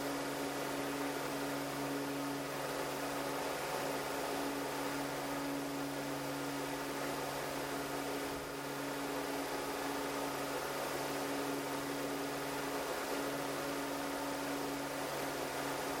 The highest frequency with the fan set at hybrid (low) mode and its full speed is at 250 Hz, with a small different though from the other frequencies in the same range.
I have recorded the signals shown above, but please keep in mind that I’ve enabled Automatic Gain Control (AGC) to do so to make it easier for you to reproduce them.
100% Fan Speed